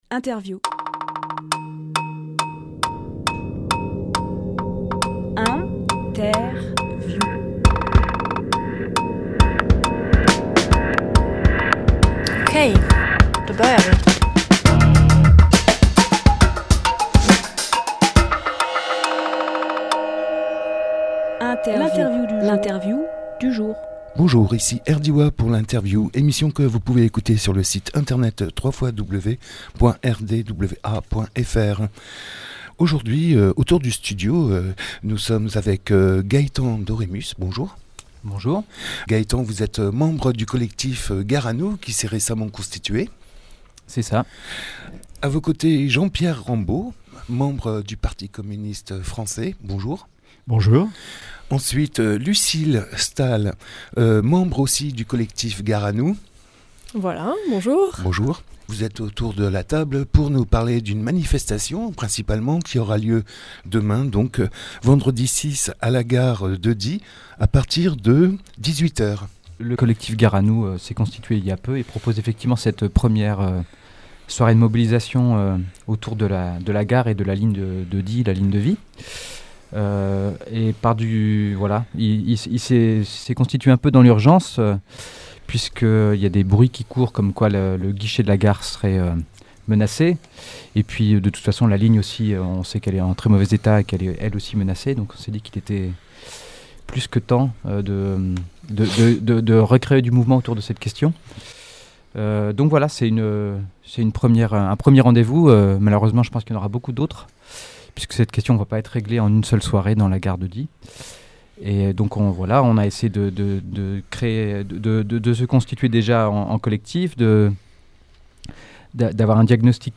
Emission - Interview Gare à nous !
Lieu : Studio RDWA